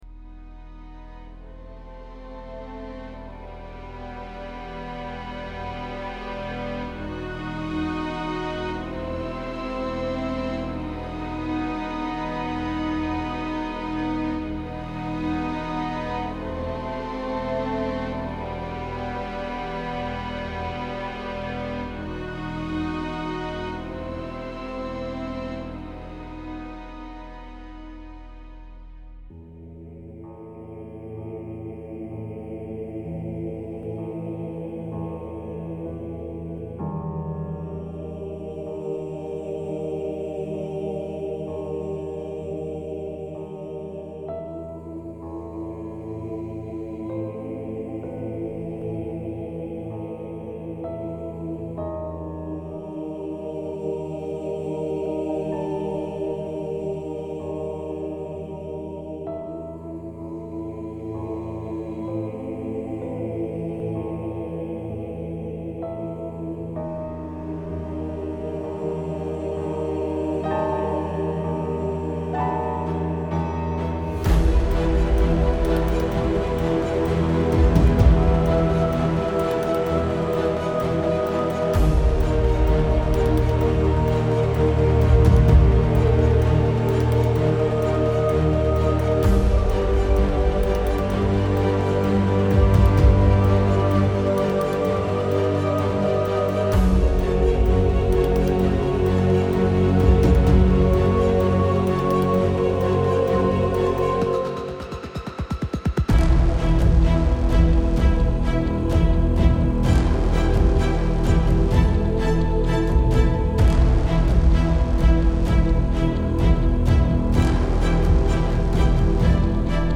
My random left over orchestra